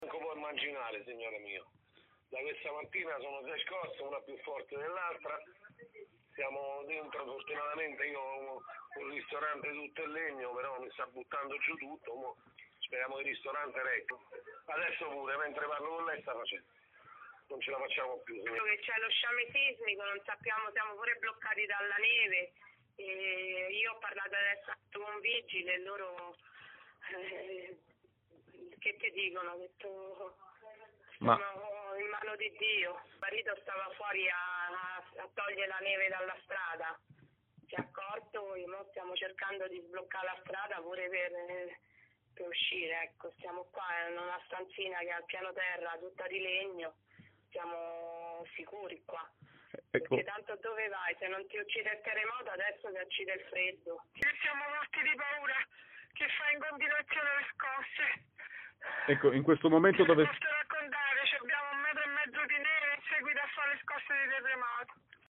le testimonianze dei cittadini
Montereale è l’epicentro: la paura per le scosse di oggi si aggiunge alla paura per il terremoto passato e per le condizioni proibitive del tempo, come raccontano al nostro microfono alcuni abitanti della cittadina.
TESTIMONIANZE-TERREMOTO.mp3